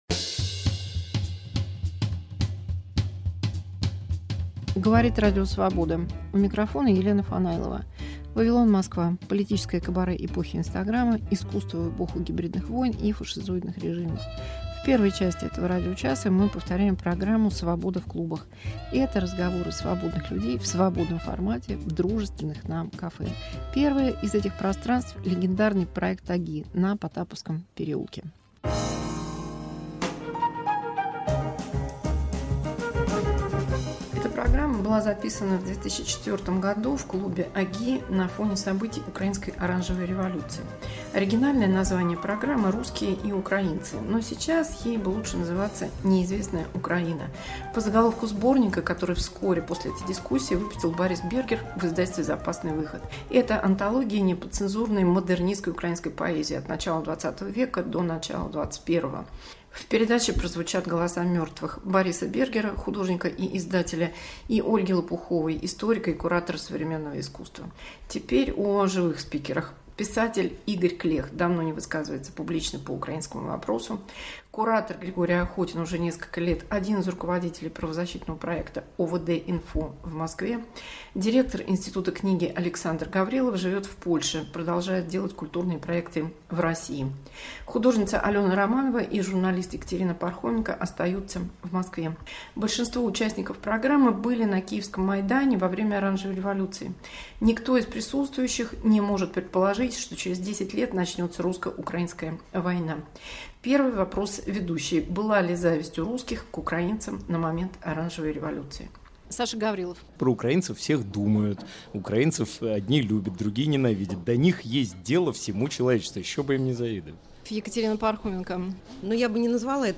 Споры из архива 2004 года о будущем Украины после "оранжевой революции" в московском клубе ОГИ